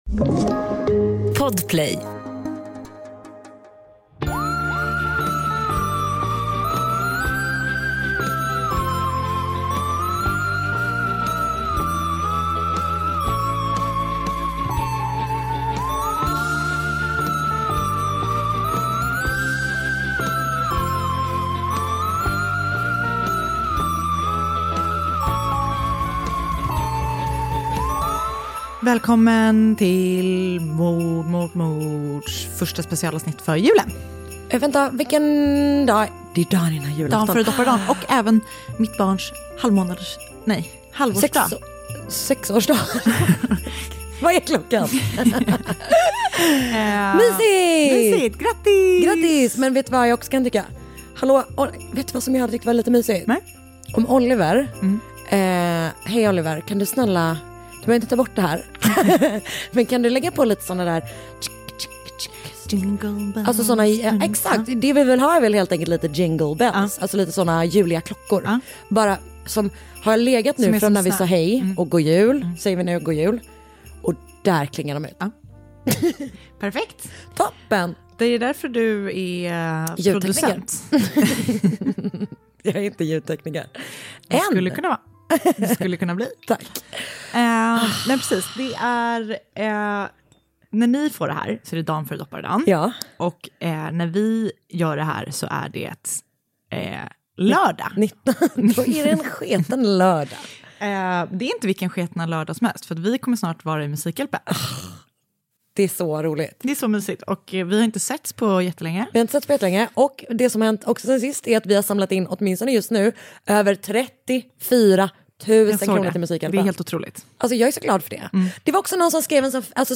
Mord Mot Mord är en vanlig snackig podd, fast om mord. Det är lättsamt prat i ett försök att hantera världens värsta ämne.